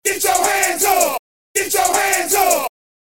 S – GET YOUR HANDS UP – 80BPM
S-GET-YOUR-HANDS-UP-80BPM.mp3